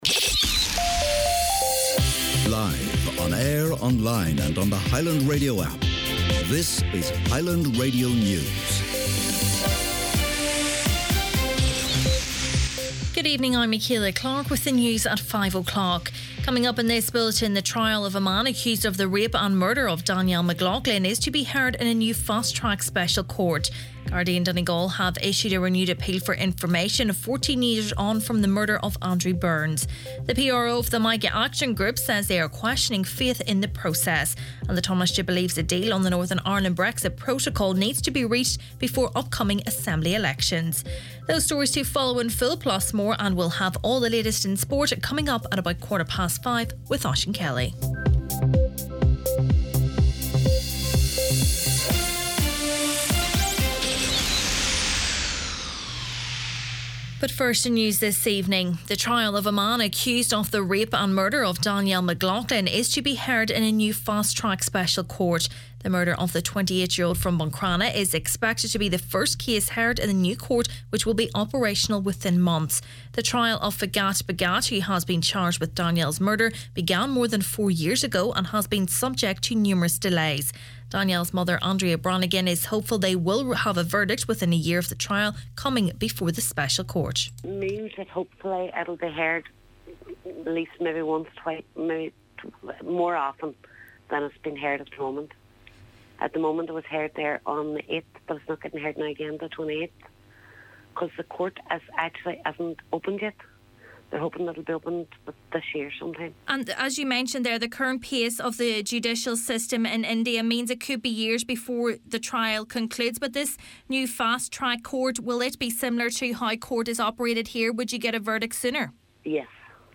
Main Evening News, Sport and Obituaries Monday February 14th